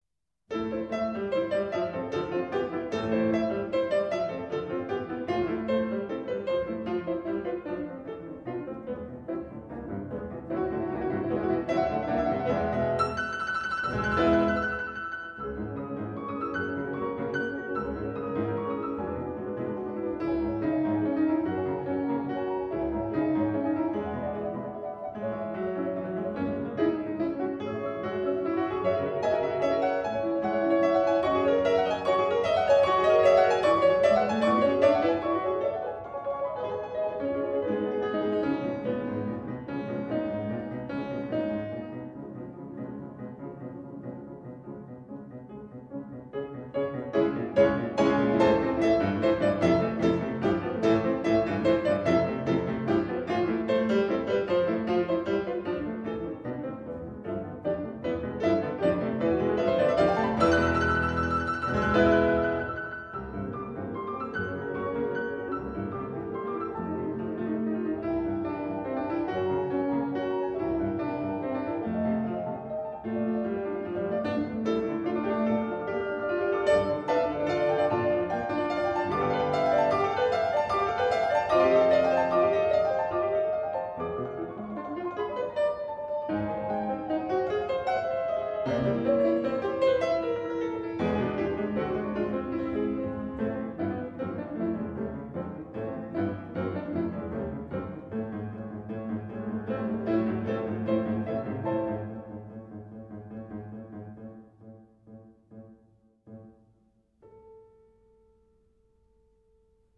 String Quartet in F major
Assez vif, très rythmé